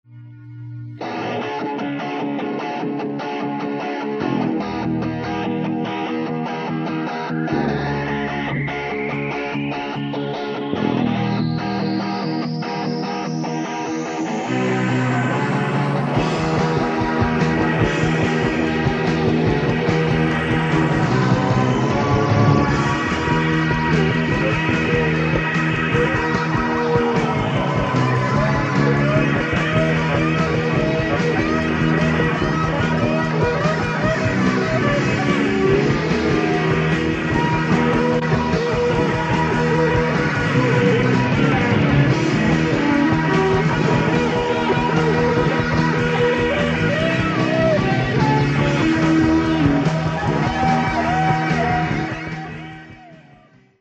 The live sound clips used are quite rare.